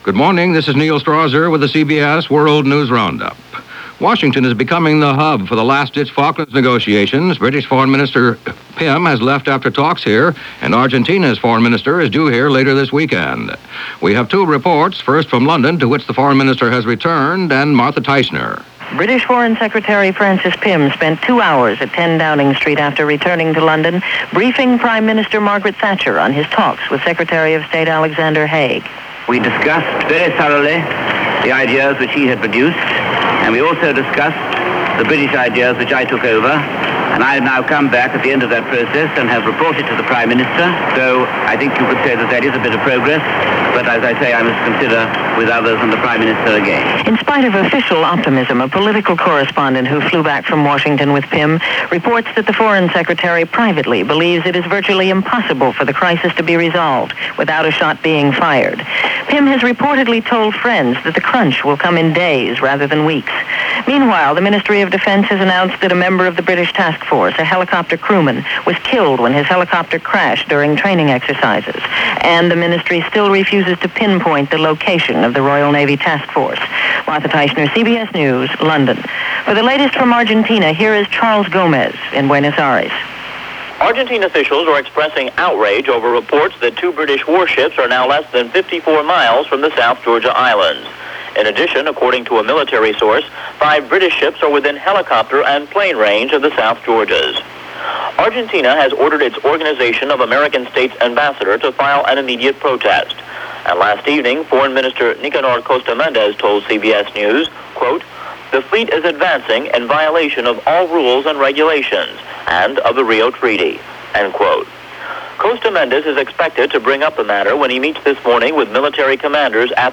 And that’s just a little of what went on this April 24, 1982 along with the Falklands crisis changing from moment to moment – all reported on The CBS World News Roundup.